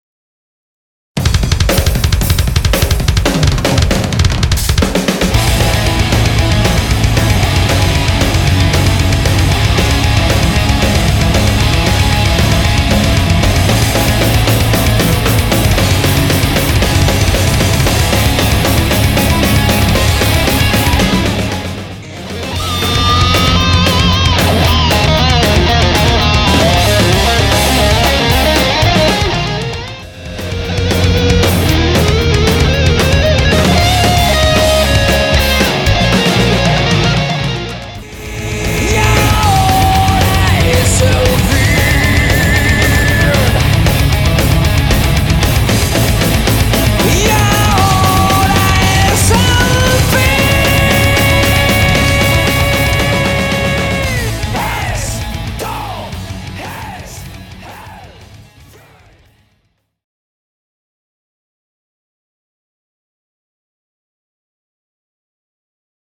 Grabado en Vilafranca Del Penedes (Barcelona)
Guitarra/Voz
Guitarra Solista
Bajo
Batería